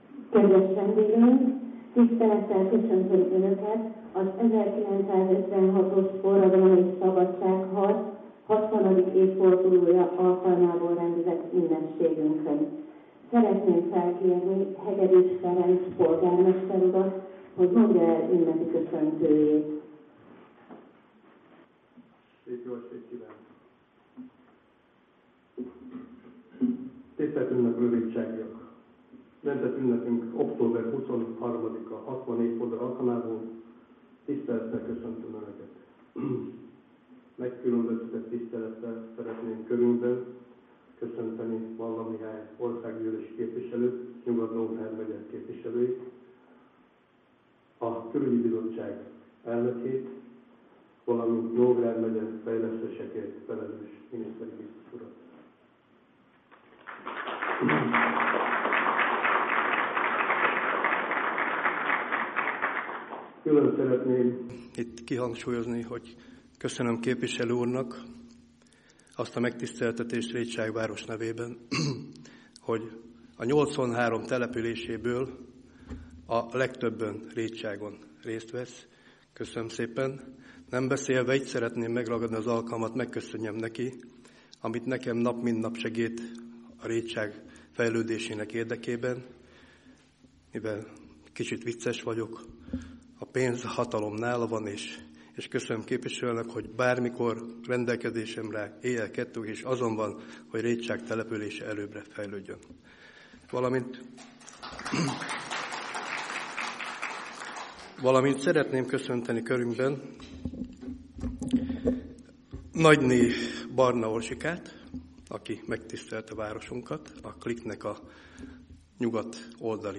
(A besz�dek meghallgathat�k az alul is jelzett linken.)